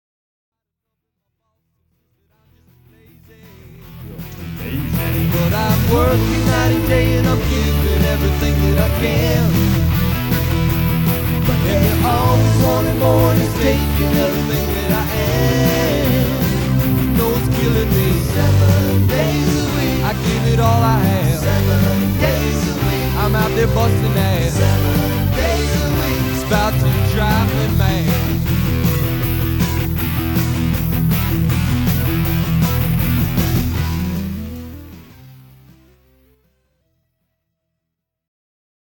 *** Bassmint Studios - Danville, VA